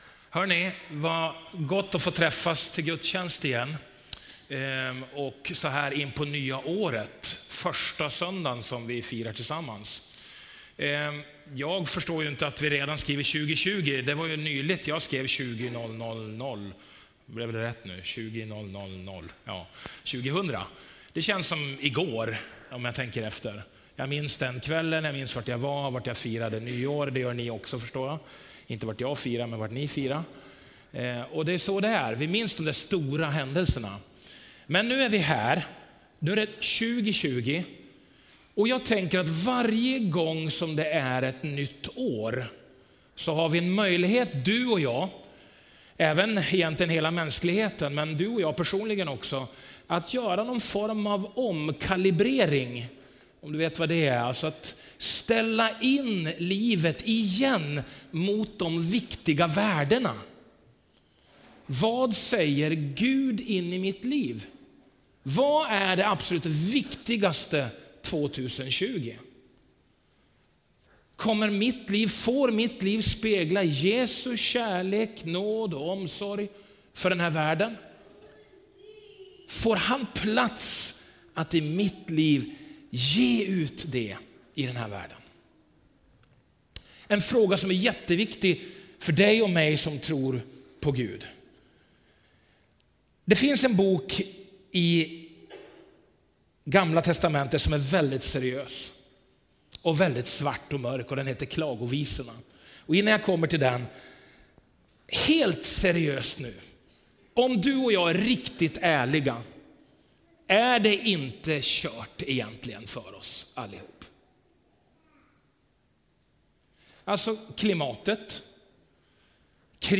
Gudstjänst 5 januari 2020